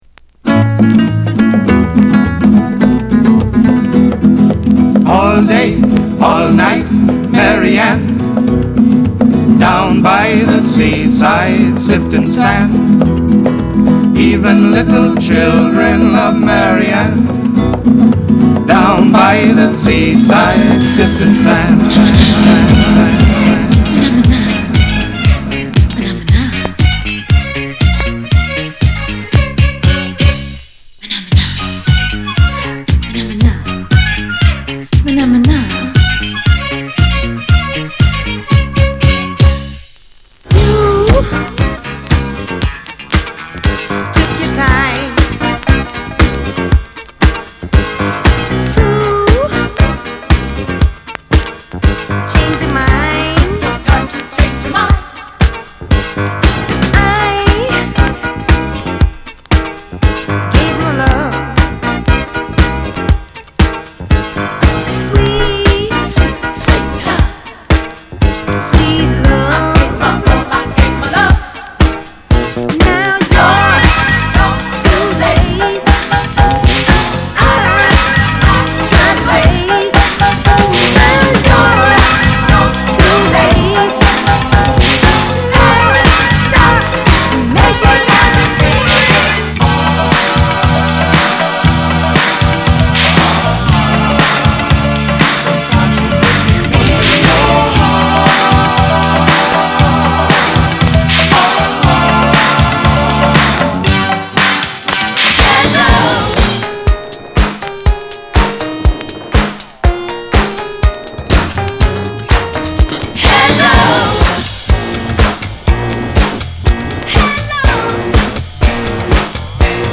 category Disco